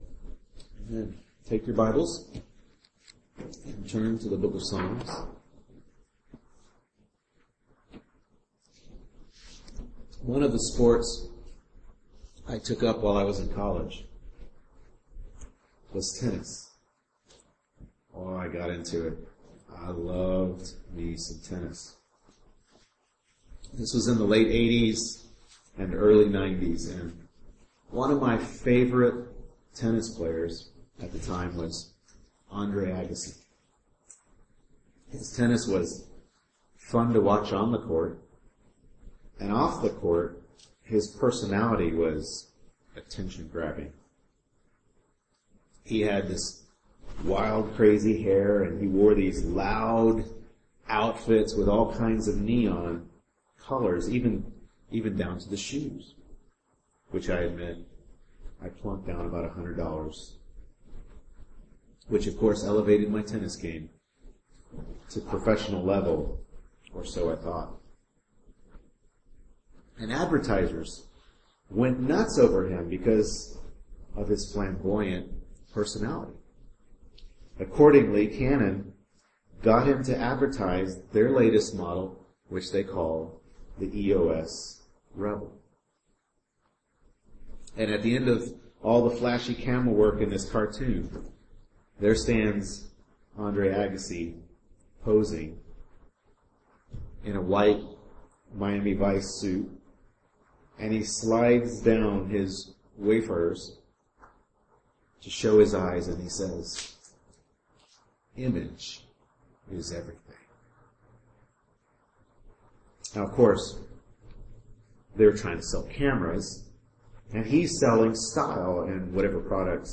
Psalm 16 Service Type: Morning Worship Service Bible Text